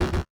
Foley Sports / Buzzers and Boards / Buzzer.wav
Buzzer.wav